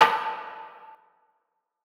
TCE TM88 ambience.wav